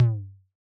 Index of /musicradar/retro-drum-machine-samples/Drums Hits/Raw
RDM_Raw_SY1-Tom02.wav